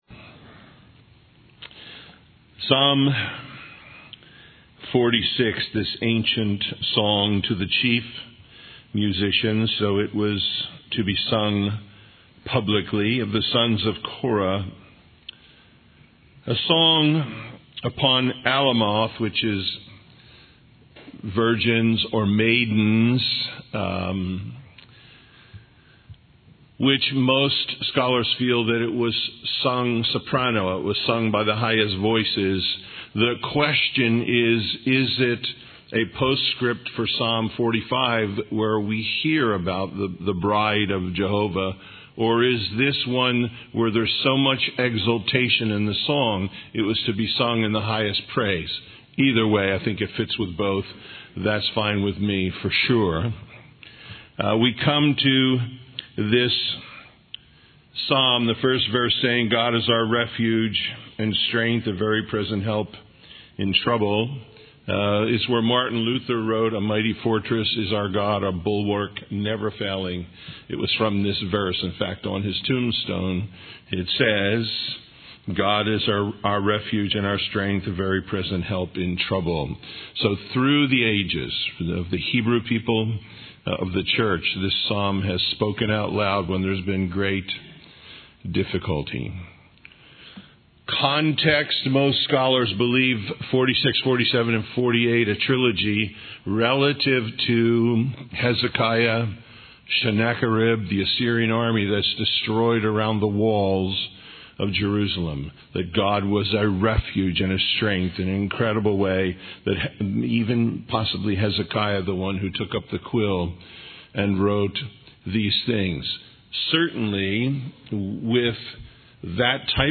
Psalms 46:1-47:9 Listen Download Original Teaching Email Feedback 46 47 God is our refuge and strength, a very present help in trouble.